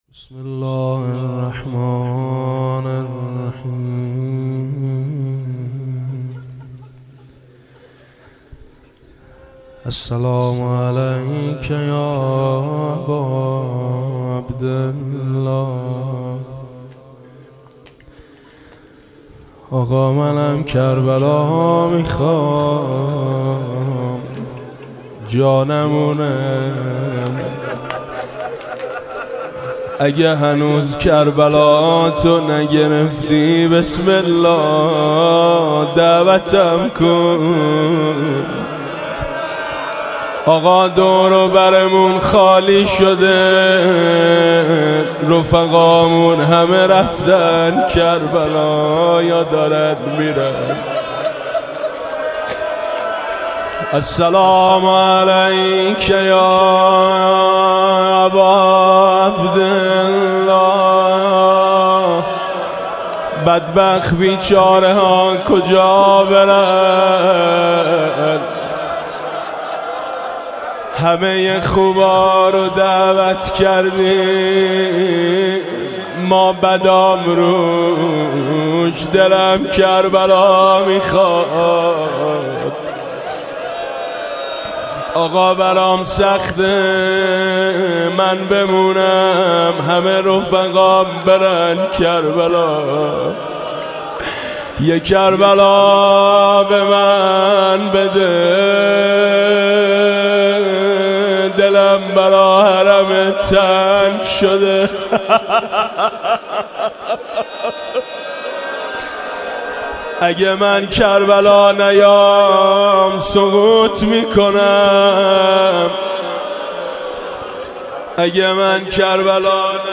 روضه - کربلا در فراقت مبتلایم